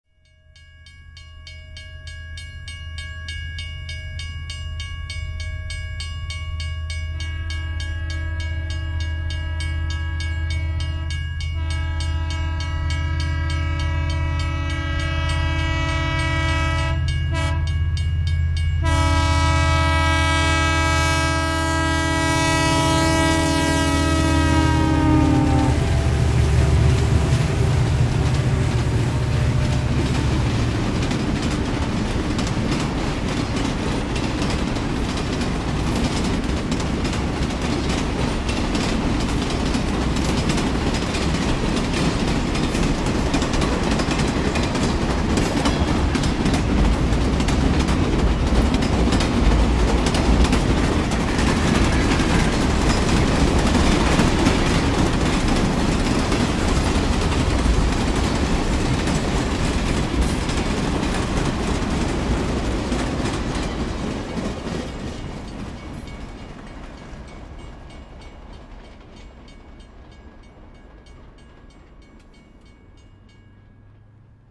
Train Approaching